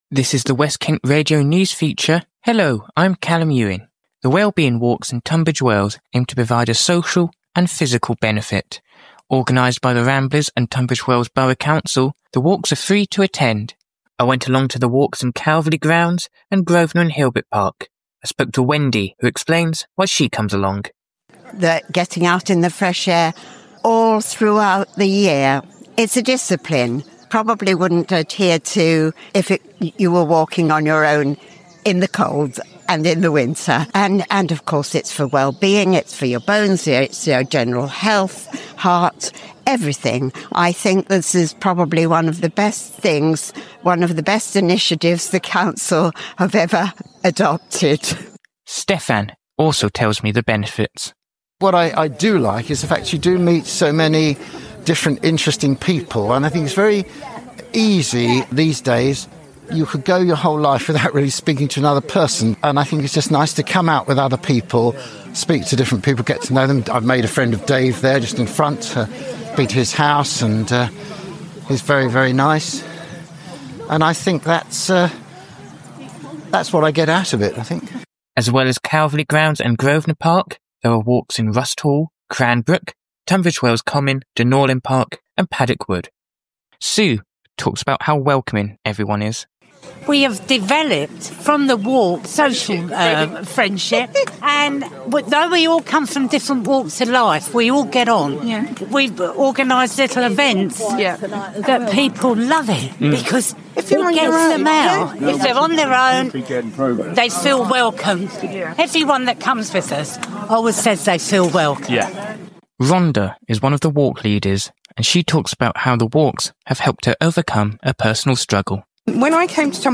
You can listen to our news feature on the Wellbeing Walks below and find out more on the Ramblers website.